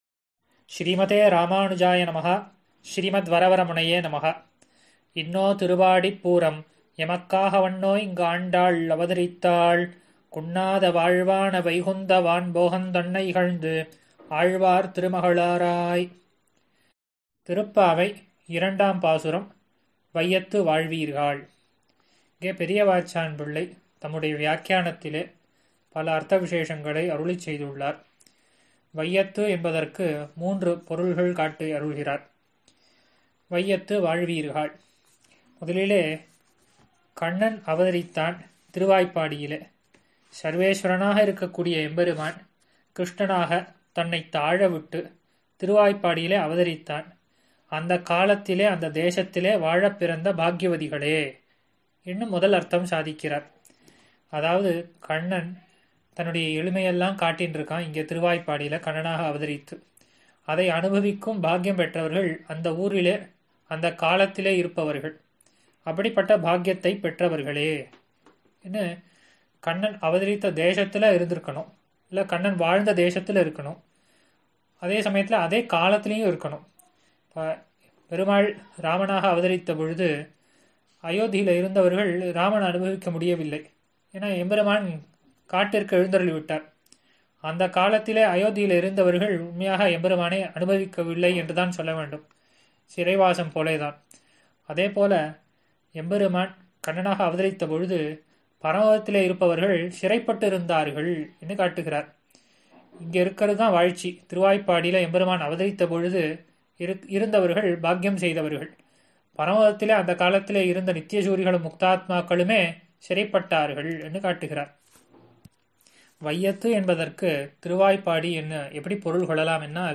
ஆறெனக்கு நின் பாதமே சரண் குழுமத்தினர் வழங்கும் சார்வரி ௵ மார்கழி ௴ மஹோத்ஸவ உபன்யாசம்